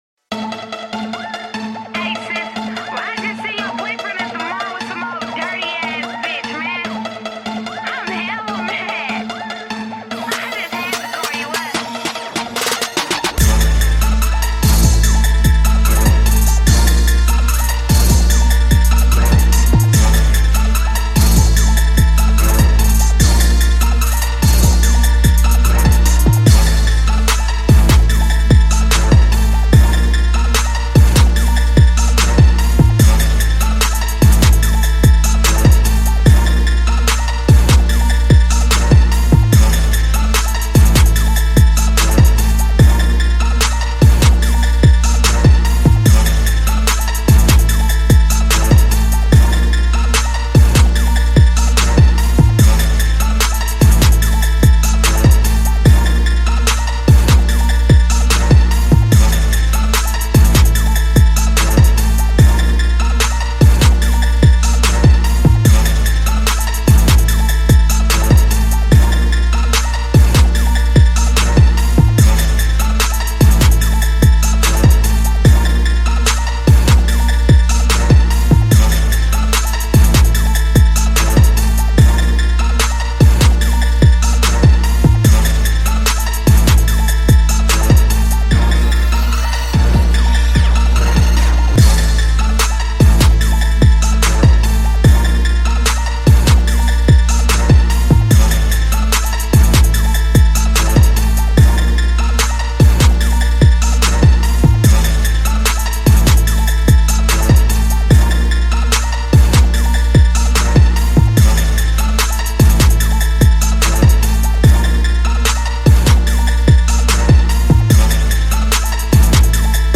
on September 24, 2022 in Trap Instrumental Archives